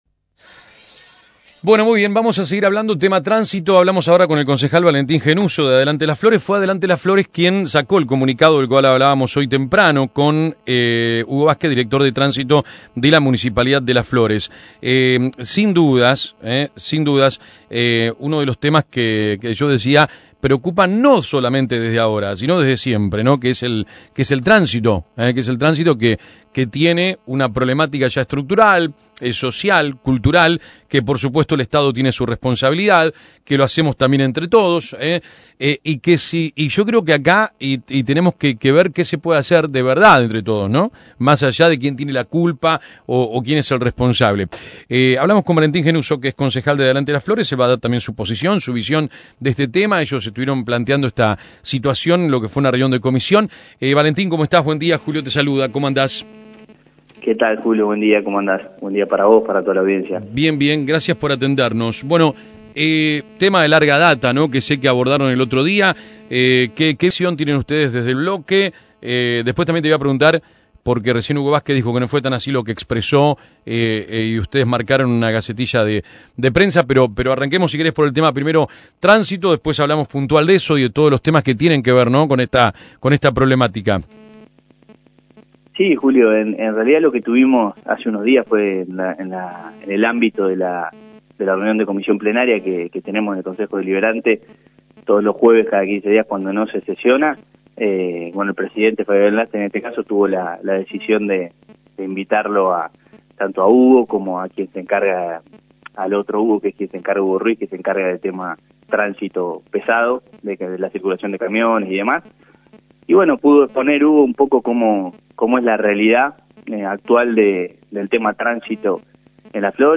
Tránsito: La palabra del Concejal valentín Gennuso (Audio Nota) - LasFloresDigital